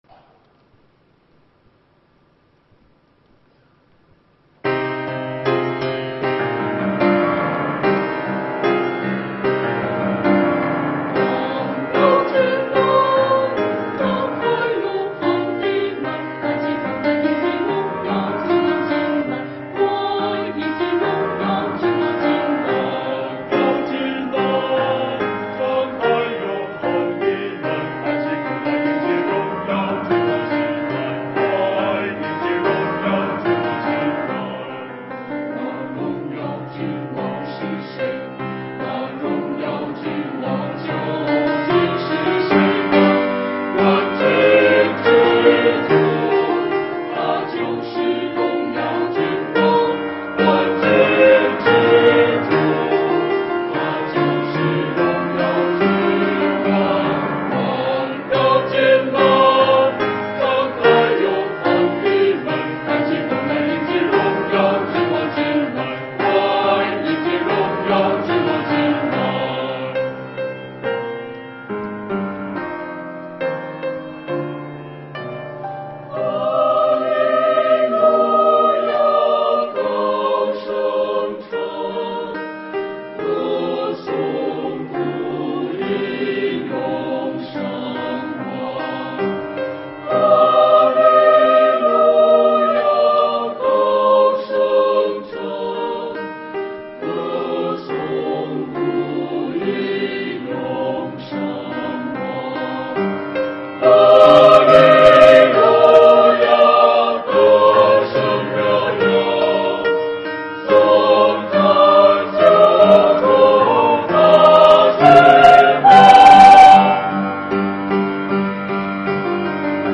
[2021年12月19日主日献唱]《王要进来》 | 北京基督教会海淀堂
团契名称: 大诗班 新闻分类: 诗班献诗 音频: 下载证道音频 (如果无法下载请右键点击链接选择"另存为") 视频: 下载此视频 (如果无法下载请右键点击链接选择"另存为")